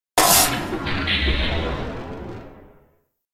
دانلود آهنگ ربات 22 از افکت صوتی اشیاء
جلوه های صوتی
دانلود صدای ربات 22 از ساعد نیوز با لینک مستقیم و کیفیت بالا